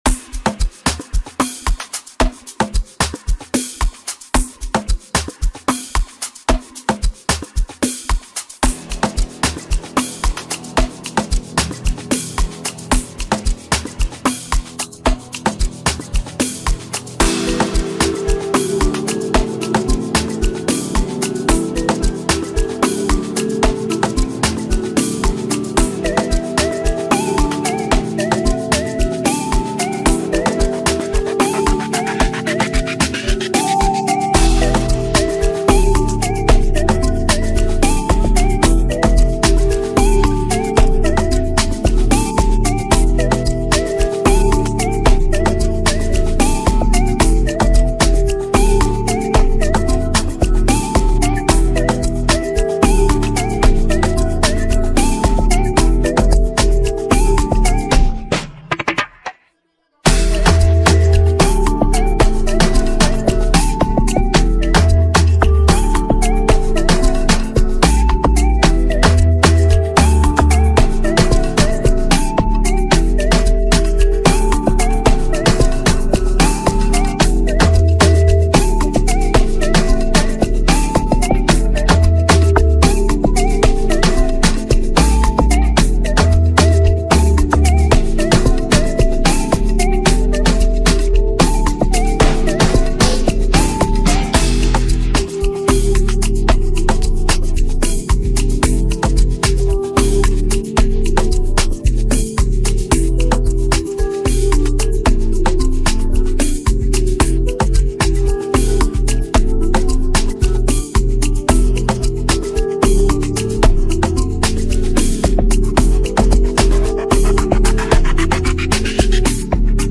Tagged afrobeats